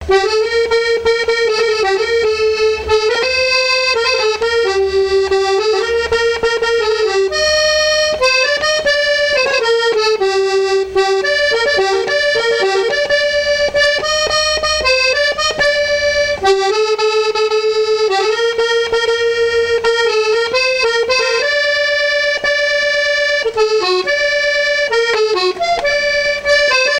Aizenay
danse : valse
Pièce musicale inédite